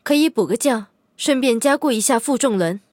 LT-35中破修理语音.OGG